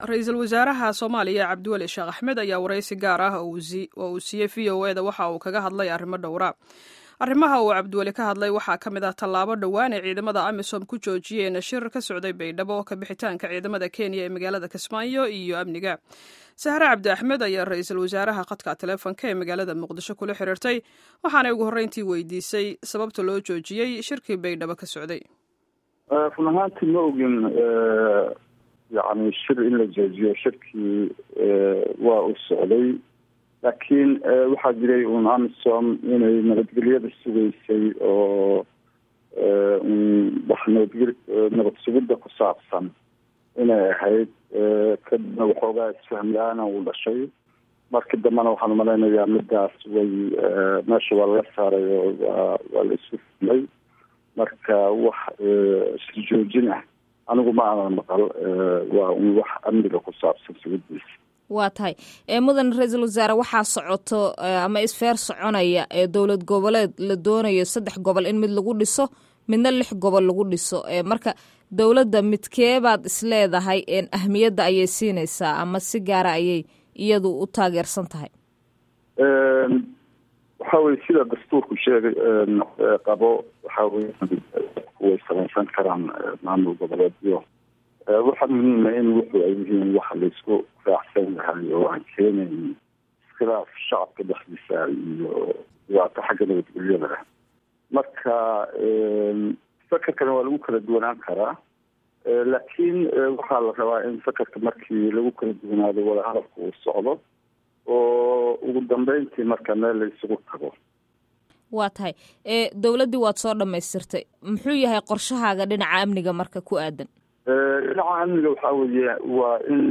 Wareysiga RW-ha Somalia